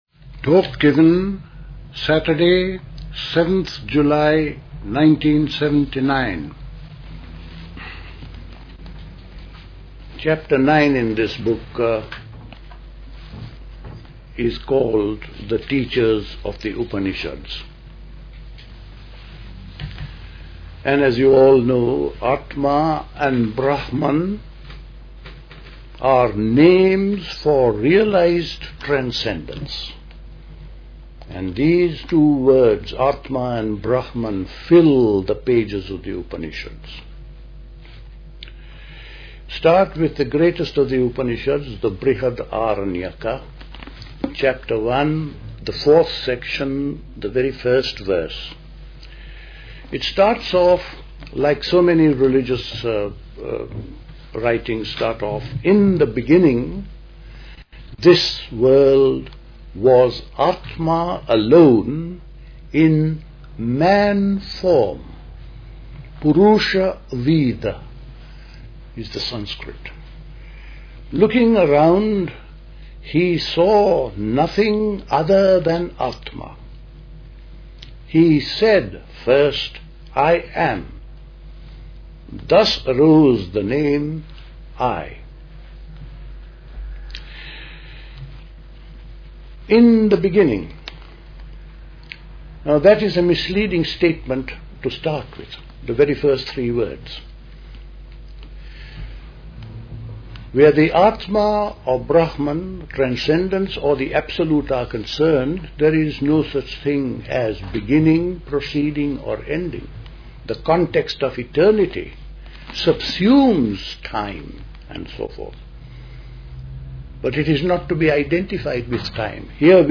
A talk
at Dilkusha, Forest Hill, London on 7th July 1979